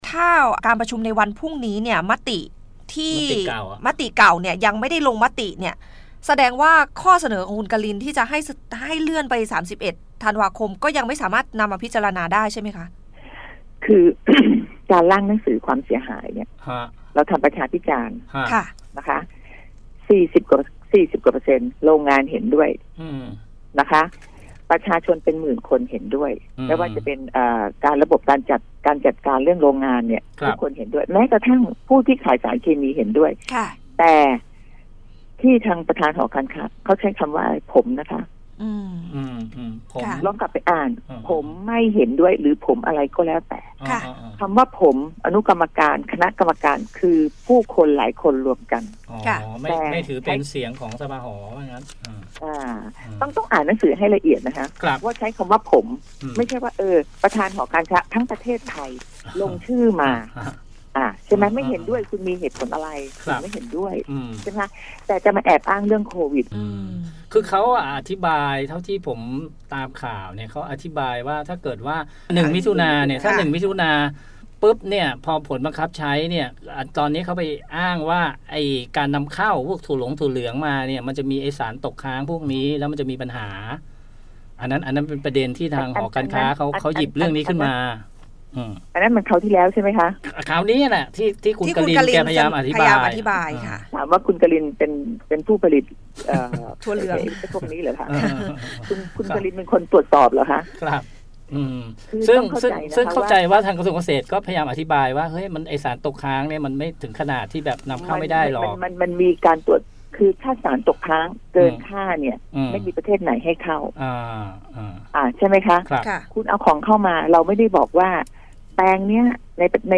สัมภาษณ์ : คุณมนัญญา ไทยเศรษฐ์ ตำแหน่ง : รัฐมนตรีช่วยว่าการกระทรวงเกษตรและสหกรณ์ ประเด็น : จุดยืนต่อเรื่องแบนสารเคมีอันตรายทางเกษตร (หลังเอกชนร้องขอขยายเวลา)